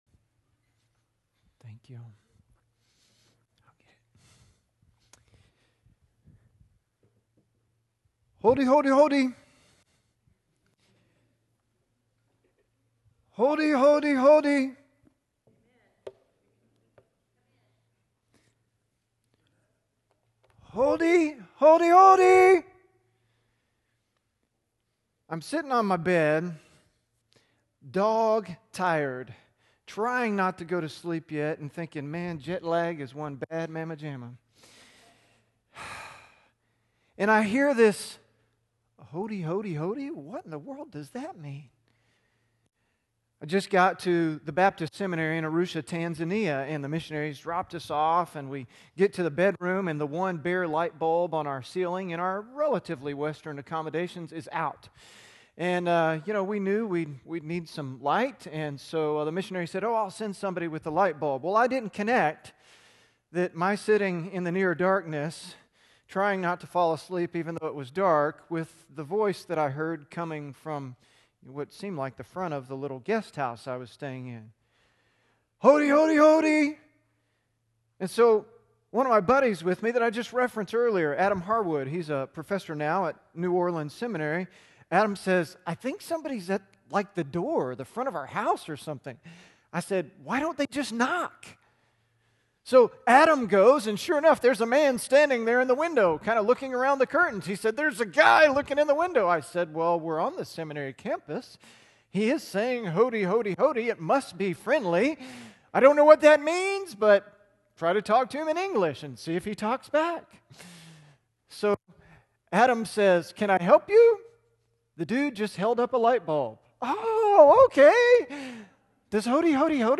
Luke 15:1-10 Sermon notes on YouVersion Following Jesus: Welcome, Sinners